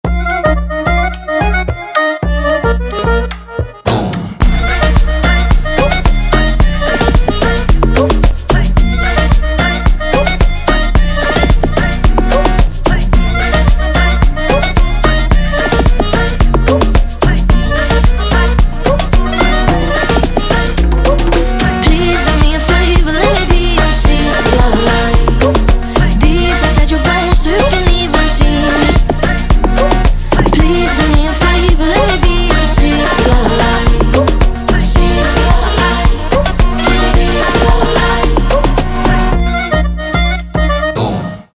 поп , танцевальные